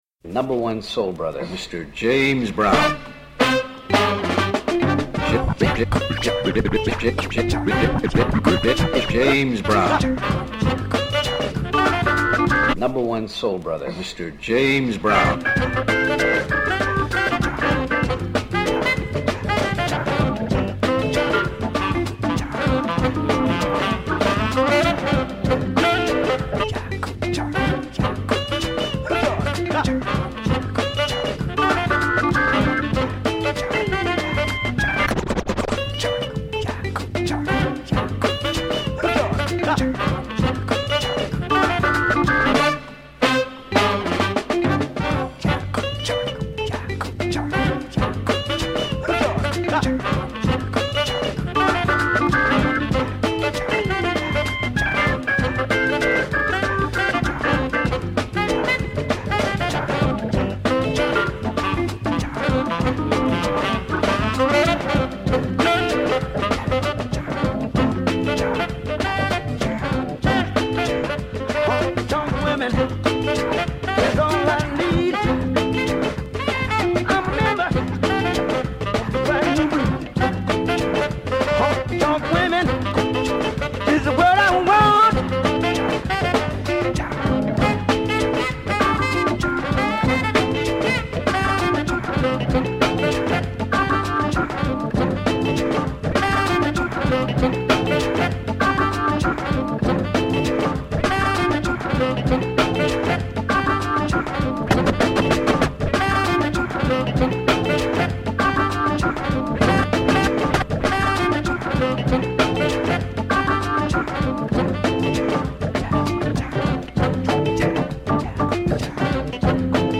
※試聴はダイジェストです。